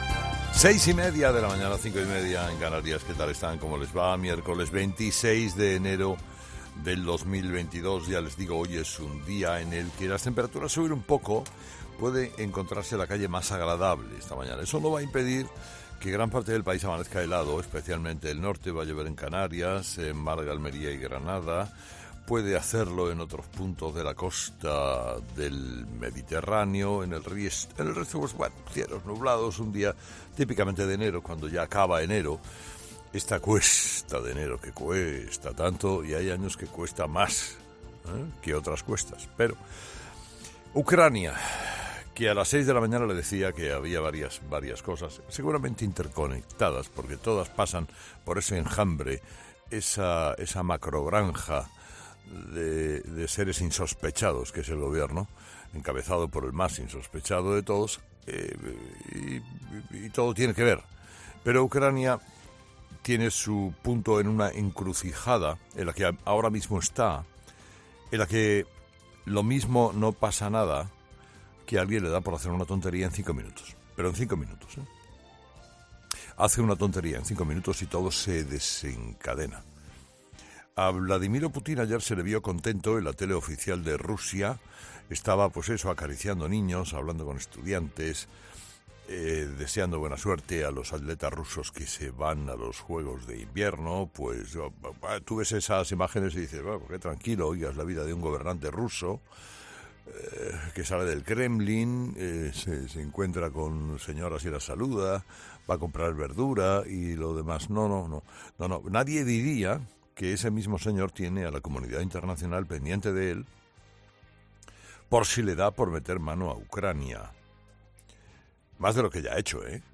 Carlos Herrera habla en Herrera en COPE sobre el fracking: ha cambiado geopolíticamente el mundo